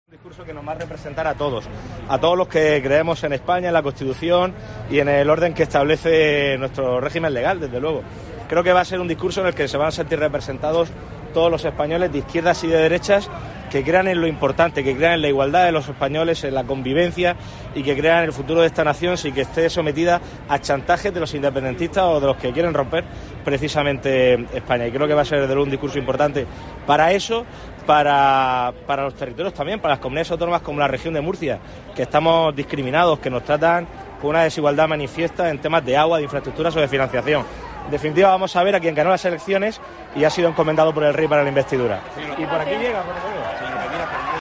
Fernando López Miras, presidente de la Región de Murcia
En declaraciones a los medios de comunicación durante su asistencia a la primera sesión del debate de investidura, López Miras ha avanzado que el discurso de Feijóo "nos va a representar a todos, a todos los que creemos en España, en la Constitución y en el orden que establece nuestro régimen legal, desde luego".